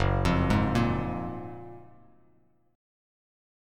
F#M9 chord